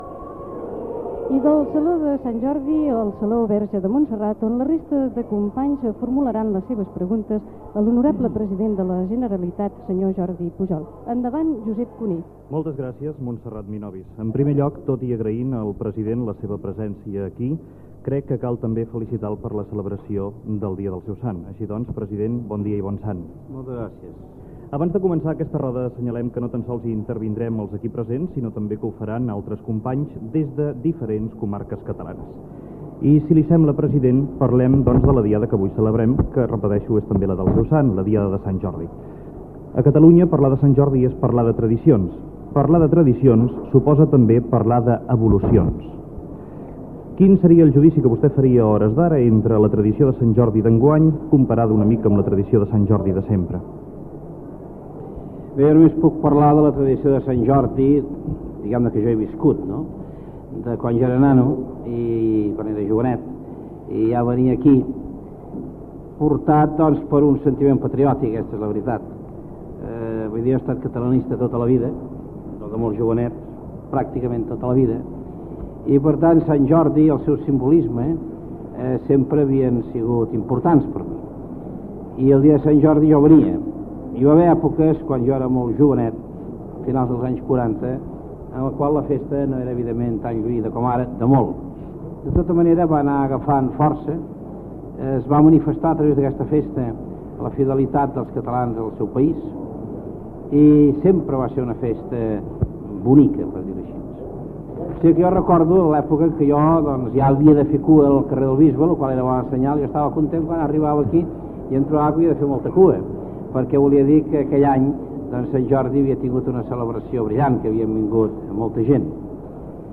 Josep Cuní entrevista al president de la Generalitat, Jordi Pujol, al Palau de la Generalitat
Info-entreteniment
El programa es va fer d'una a dues del migdia, en directe,